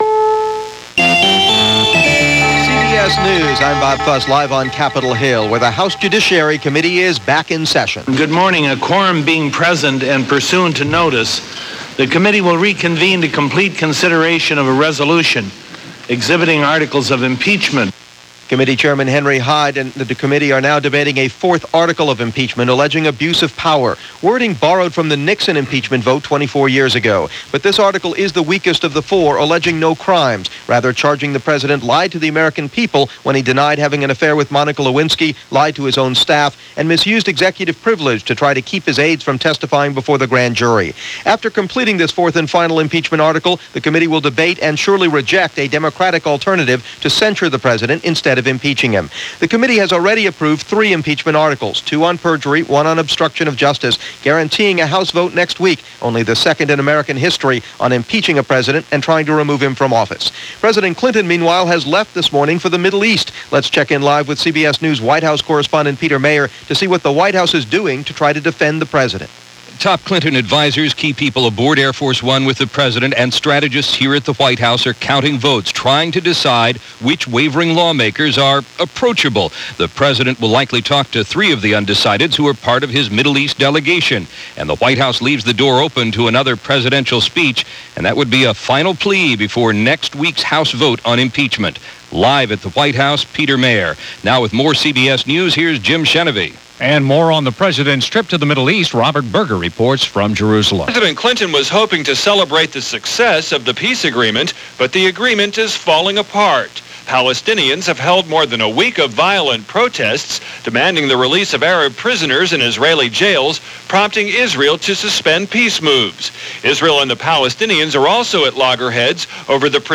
CBS World News Roundup + President’s Weekly Radio Address – December 13, 1997 – KNX, Los Angeles